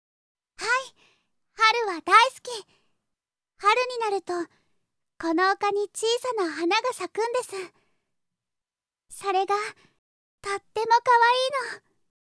・生まれつき病弱で、ほとんど外に出た事がない
【サンプルボイス】